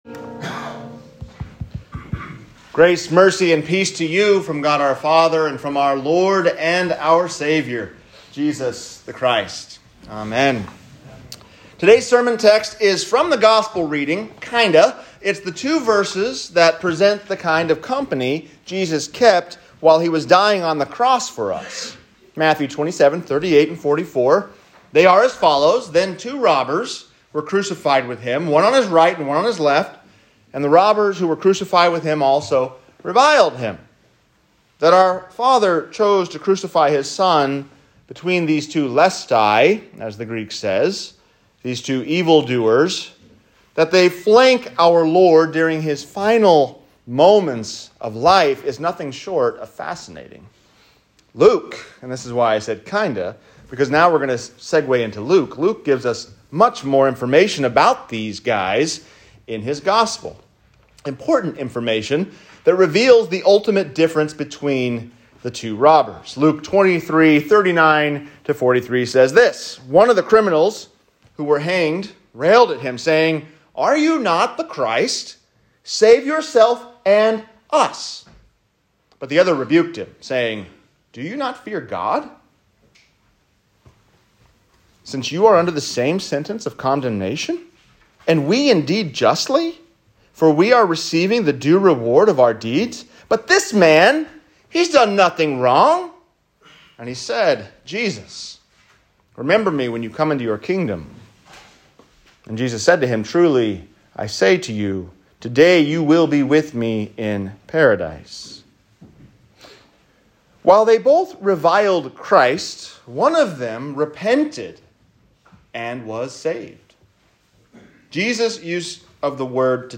4-10-22-sermon_palm-sunday.m4a